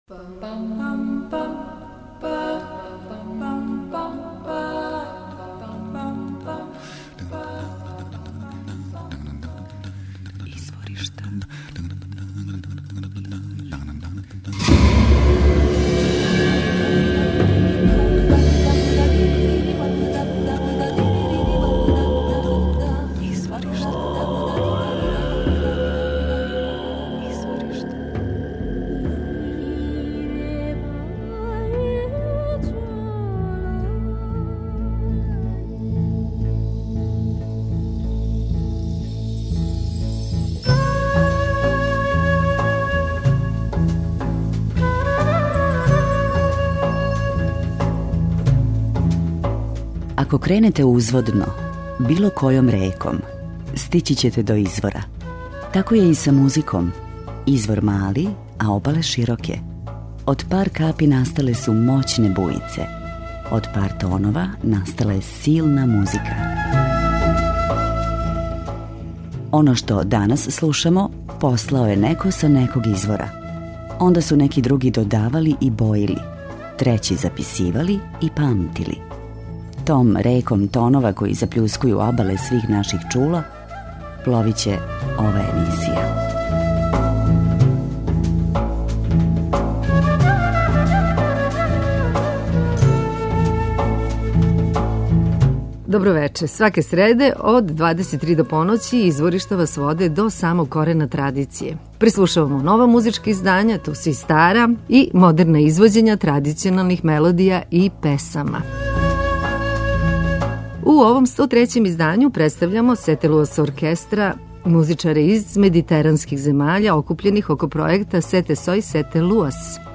удараљке
бас гитара.